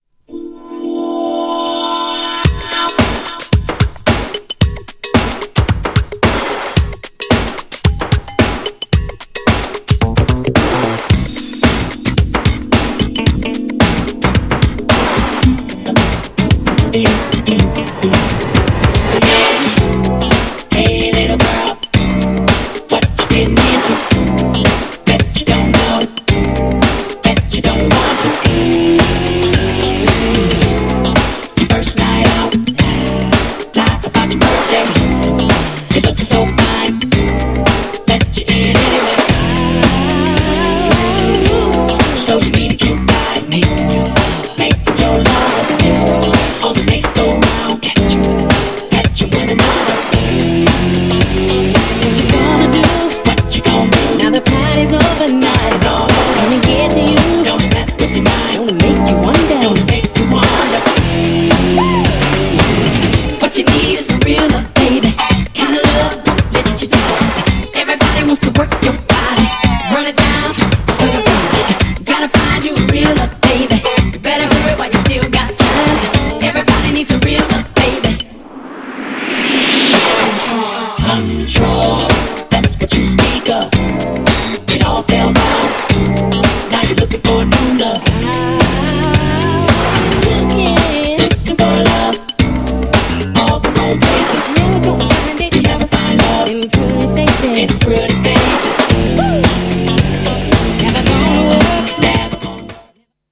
GENRE House
BPM 111〜115BPM